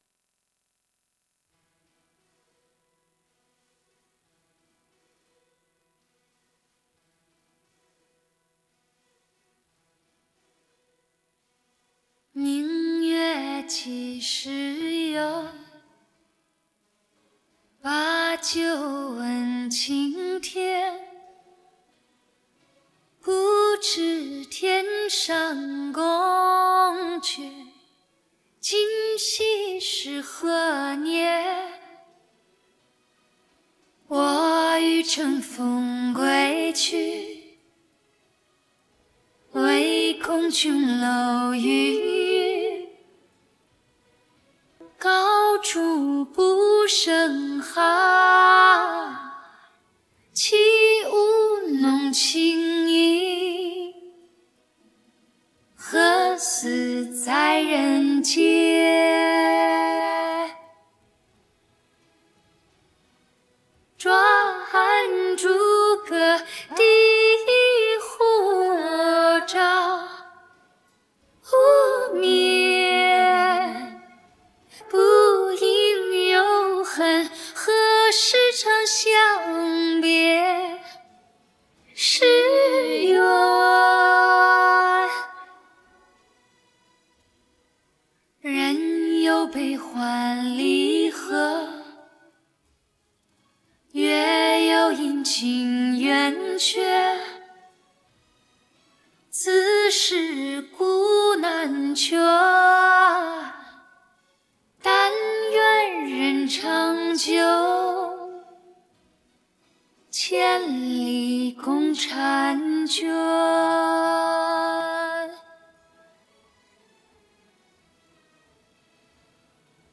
Rename 1_1_(Vocals).wav to 1.wav